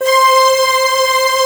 47 PAD    -R.wav